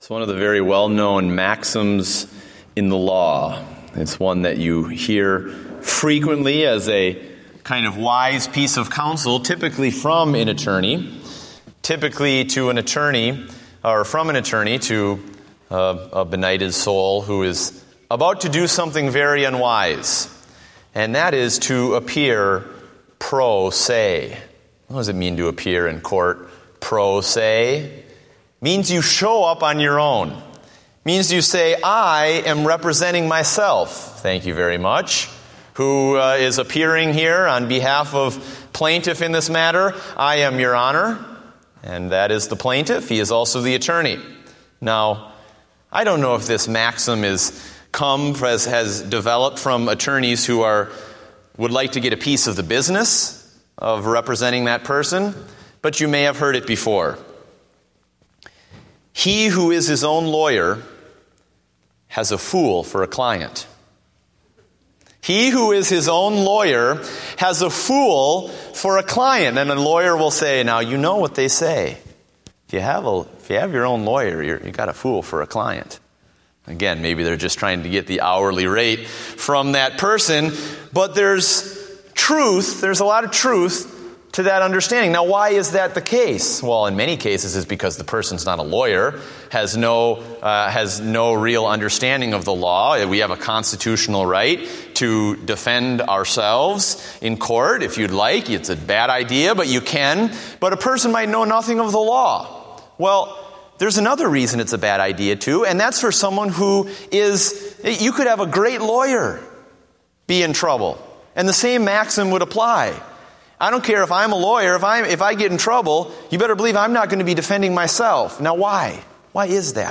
Date: September 21, 2014 (Evening Service)